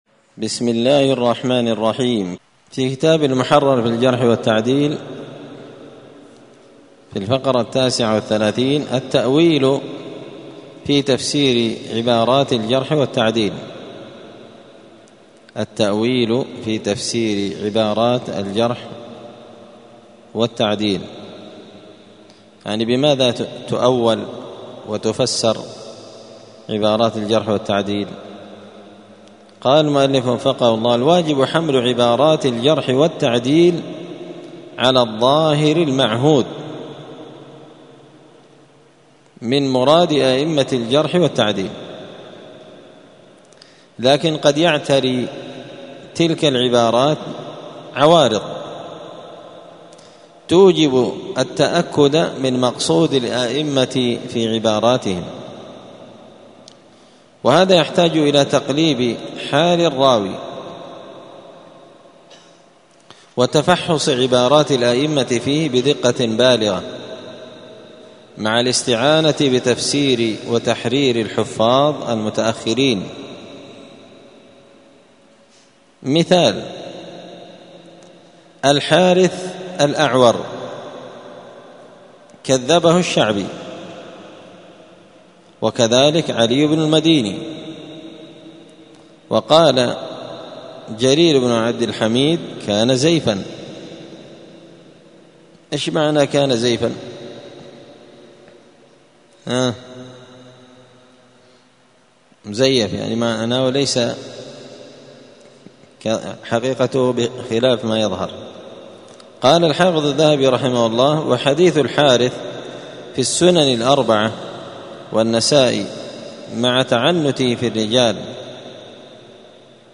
دار الحديث السلفية بمسجد الفرقان بقشن المهرة اليمن 📌الدروس اليومية